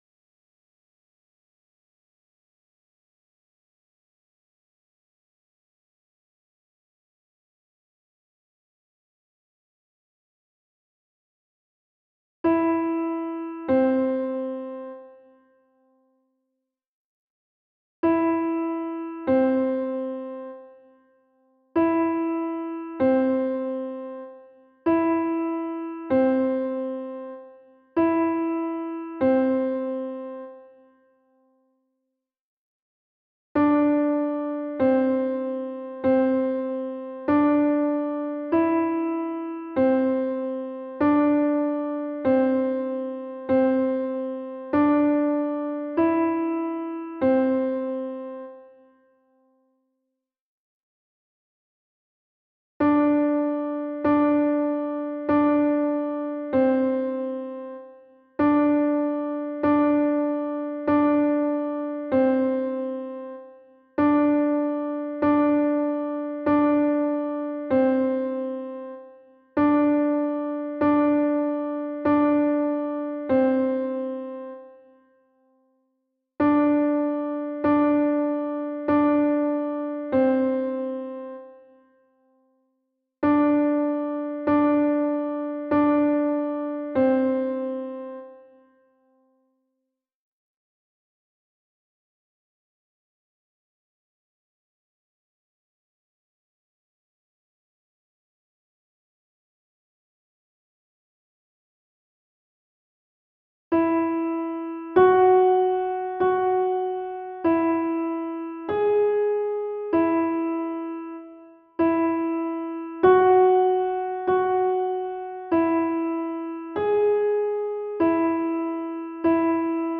3′ score for voices
piano instrumental